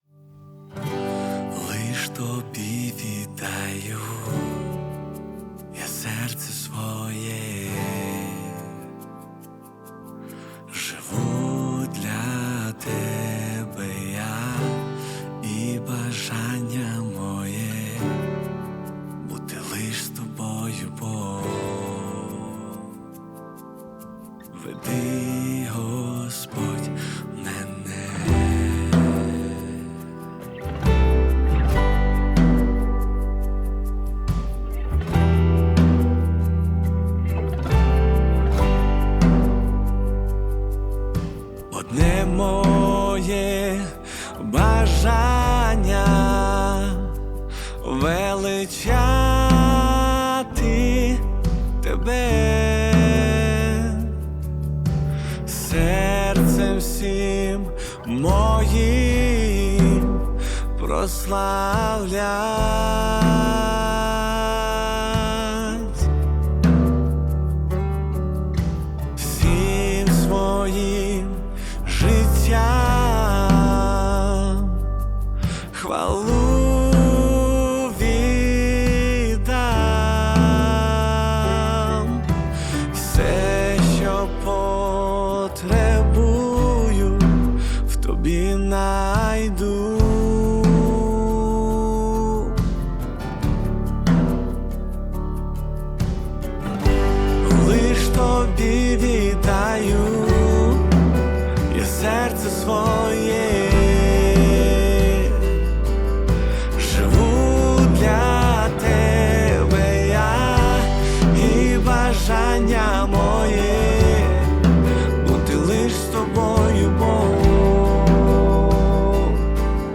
572 просмотра 345 прослушиваний 21 скачиваний BPM: 70